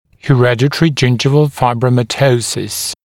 [hɪ’redət(ə)rɪ ‘ʤɪnʤɪvəl ˌfaɪbrəumə’təusəs] [he-][хи’рэдэт(э)ри ‘джиндживэл ˌфайброумэ’тоусэс] [хэ-]наследственный фиброматоз десен